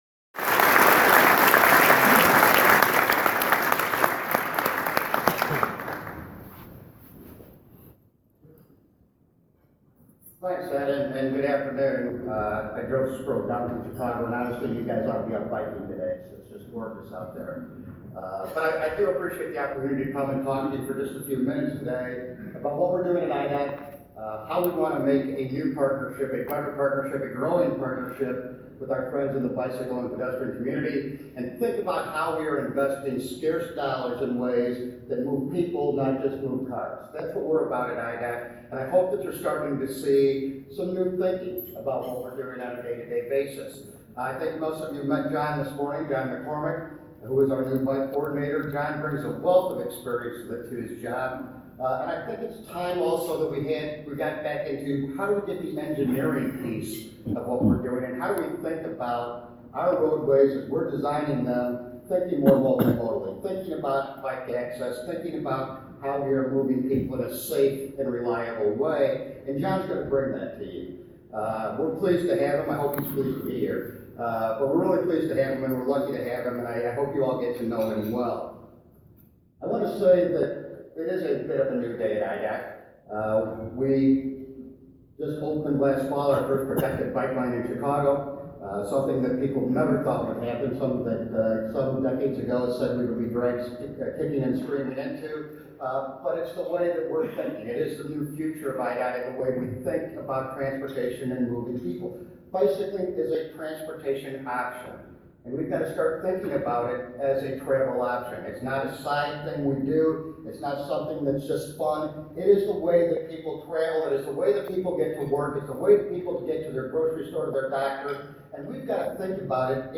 We were honored to have had IDOT Secretary Randy Blankenhorn (pictured above, center) give an inspiring speech about IDOT’s new thinking and approach to bicycle planning across the state.
Il-Bike-Summit-3-IDOT-Sec-of-Trans-Blankenhorn.mp3